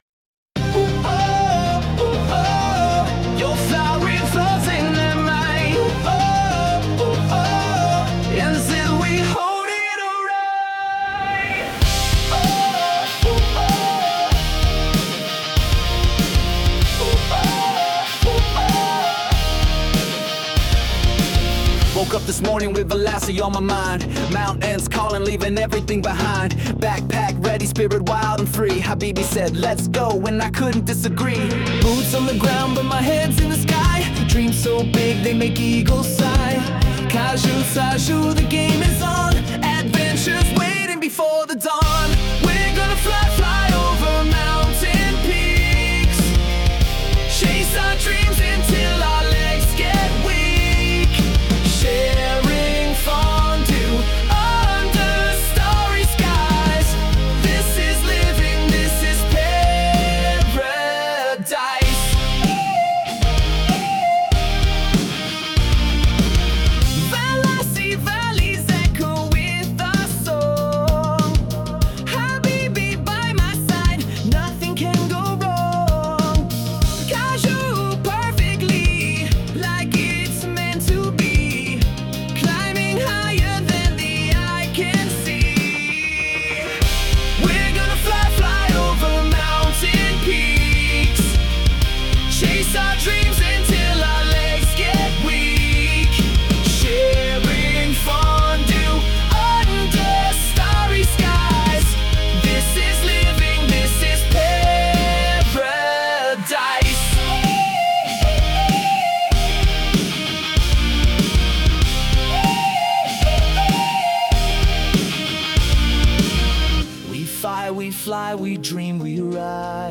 Une démonstration en direct
Après avoir utilisé Claude pour générer les paroles et Suno pour la composition, il a démontré la puissance créative des outils d’IA actuels, avec deux créations d’environ 3 minutes, mêlant rock et r’n’b, qui ont impressionné l’auditoire présent.